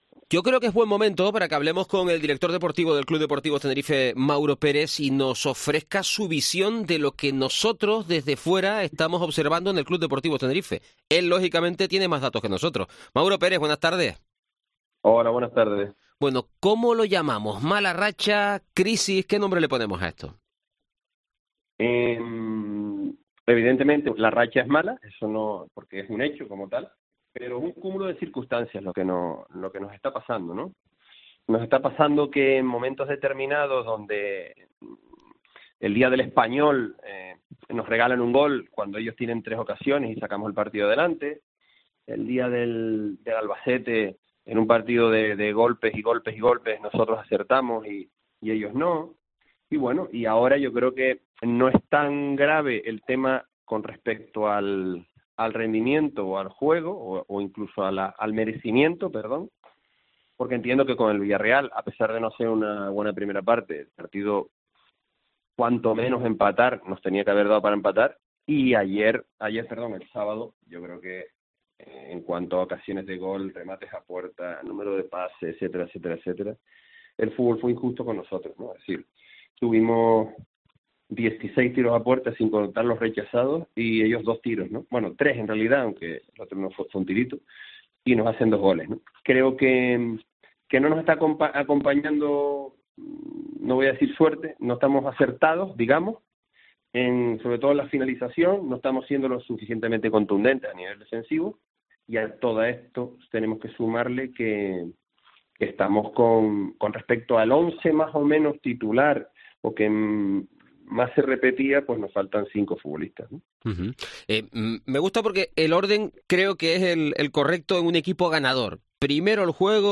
Durante la entrevista